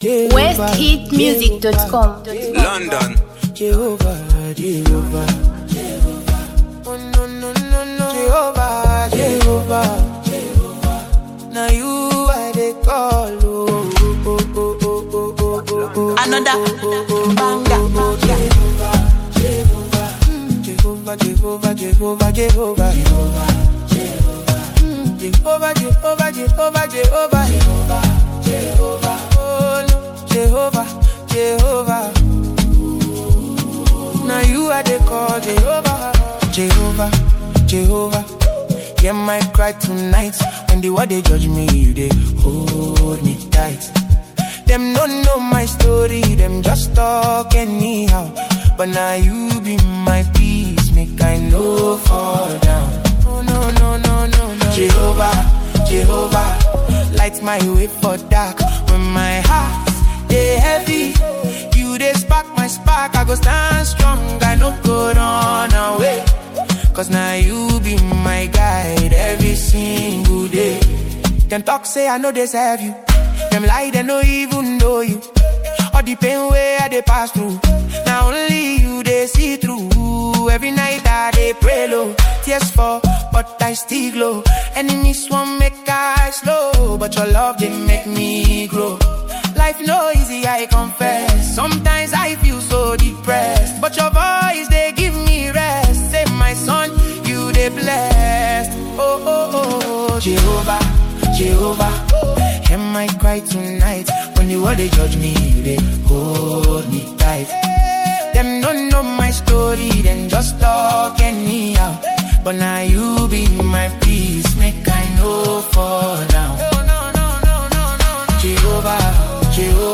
This song is a Gospel piece
With its uplifting melody and heartfelt lyrics
blend contemporary sounds with traditional Gospel elements